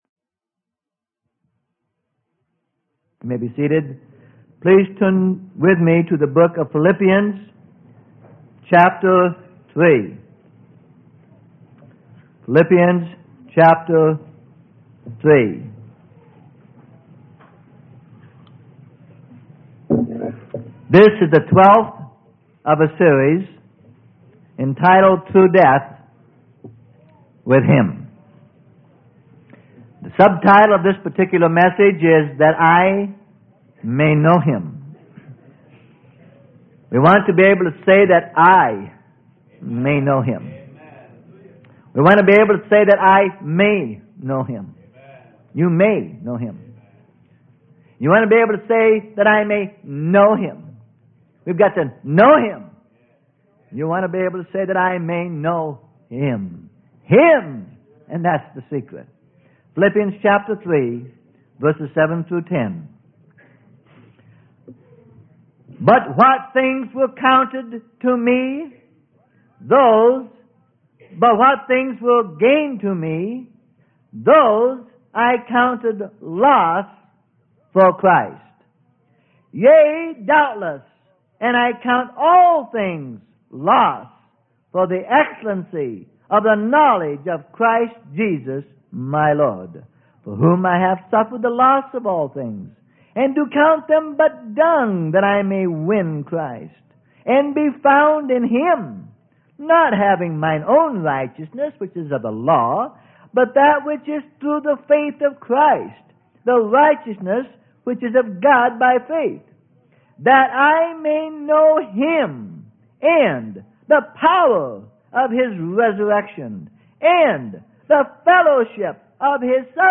Sermon: Through Death With Him - Part 12: That I May Know - Freely Given Online Library